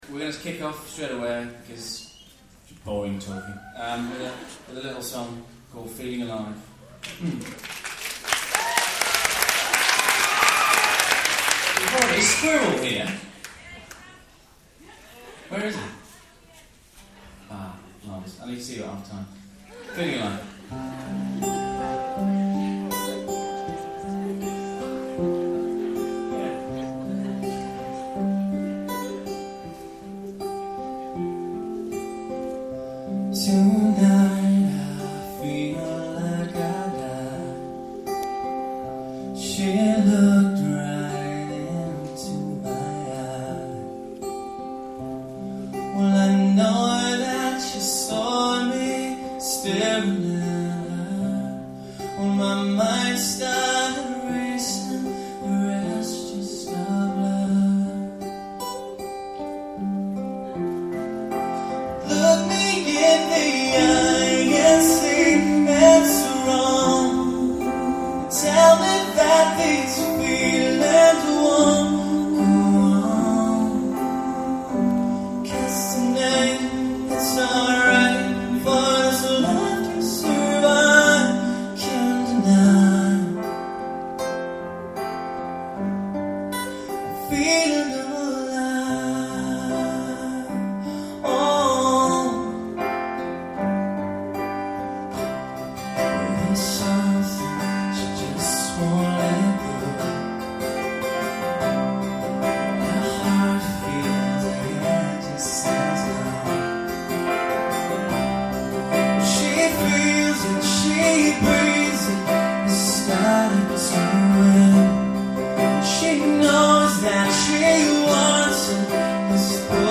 Acoustic Set